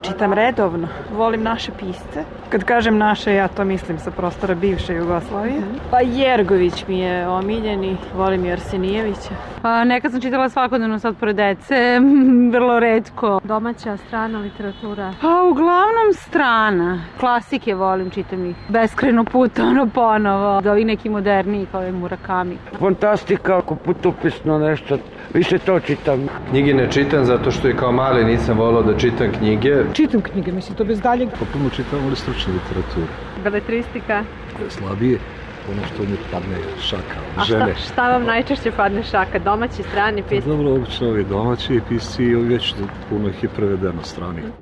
Anketa: Šta građani Srbije čitaju